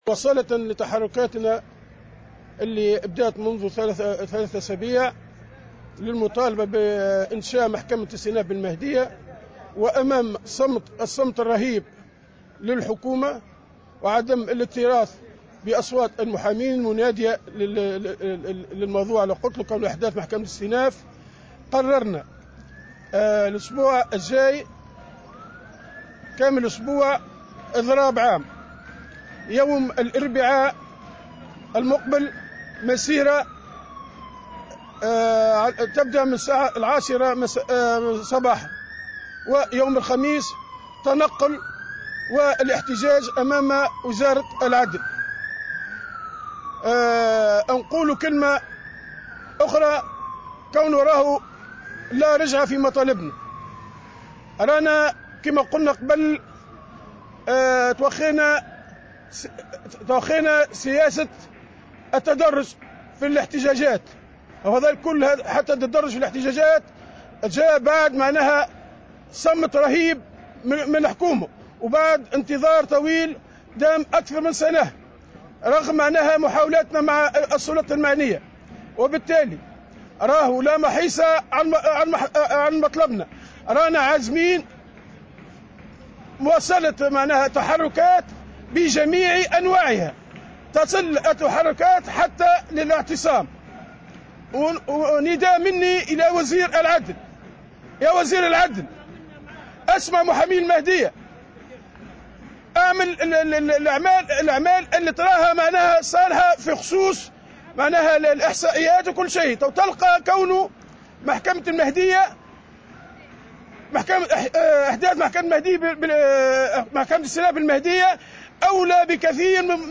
في تصريح لمراسل الجوهرة اف ام